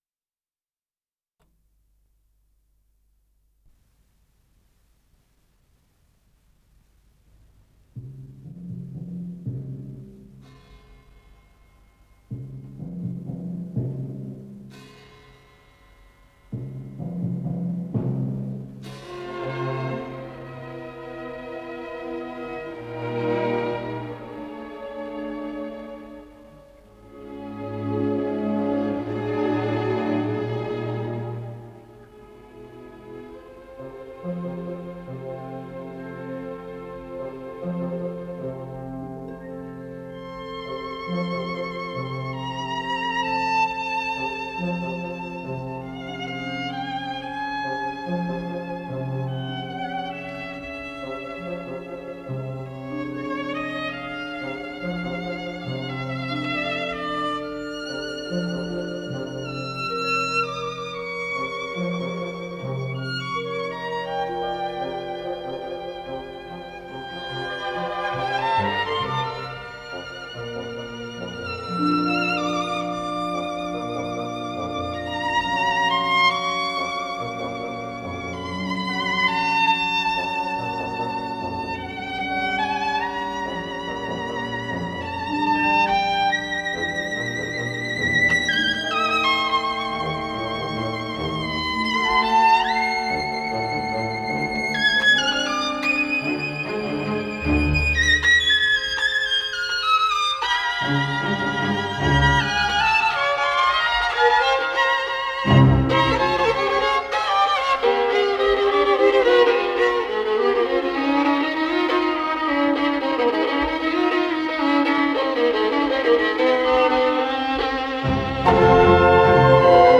скрипка
Ре минор